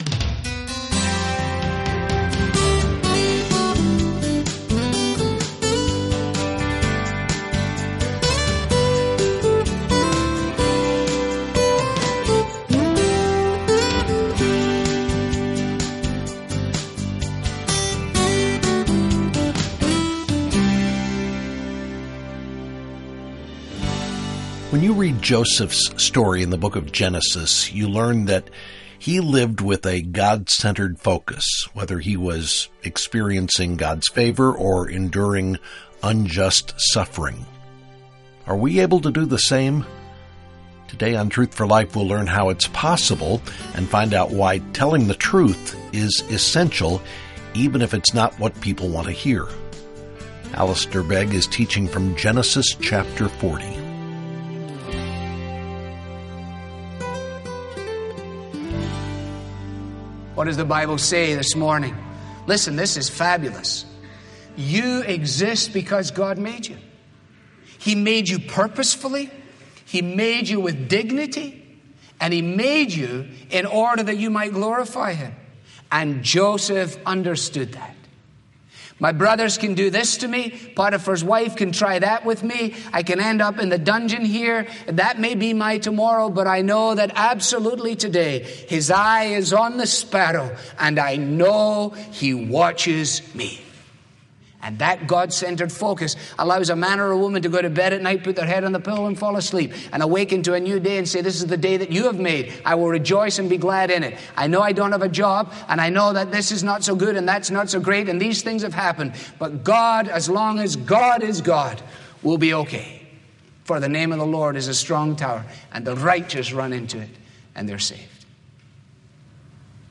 This program is part of the sermon